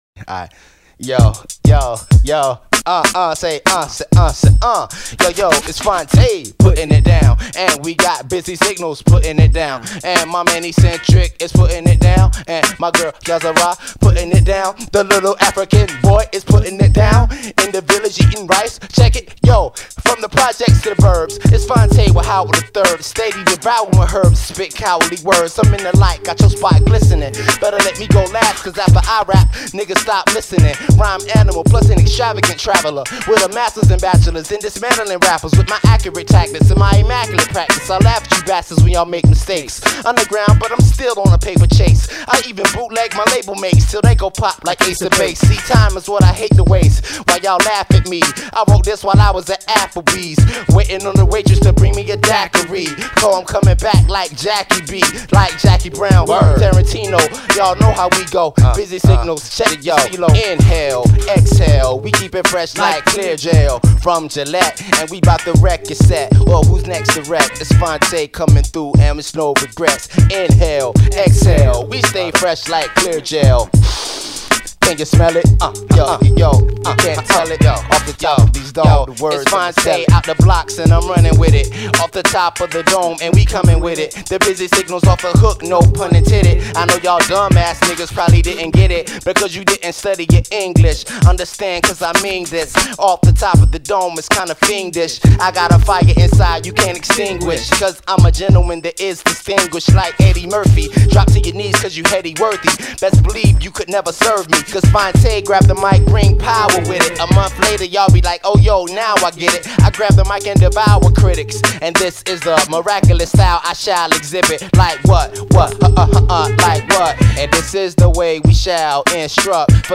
over a pretty basic beat
Just wanted to say this is a nice groover.